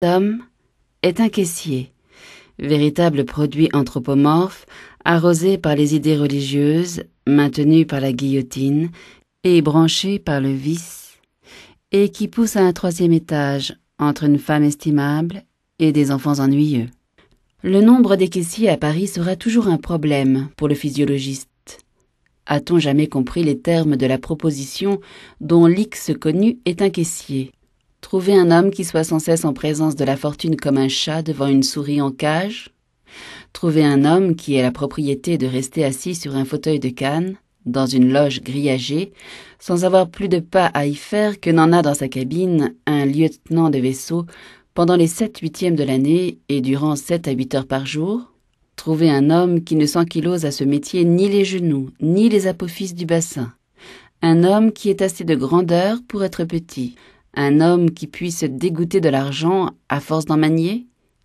Diffusion distribution ebook et livre audio - Catalogue livres numériques